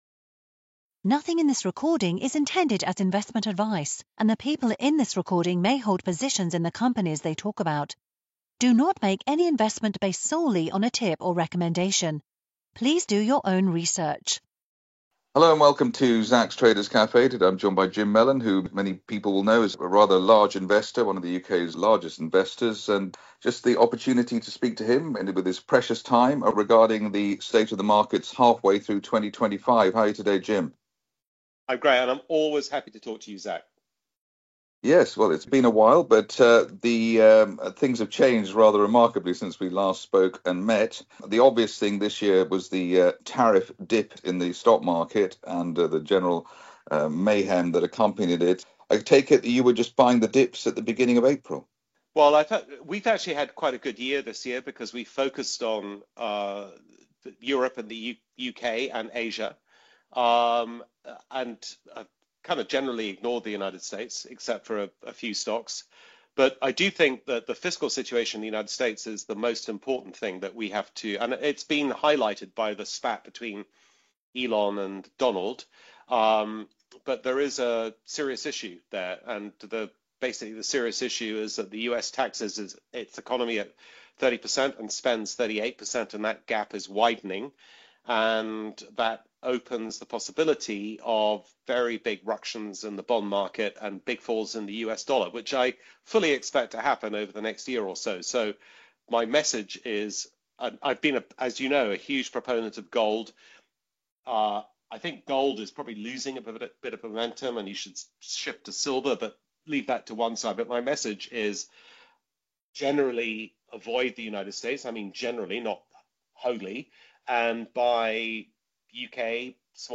Interview with Jim Mellon: Insights on the 2025 Market Landscape and Investment Strategies 9:15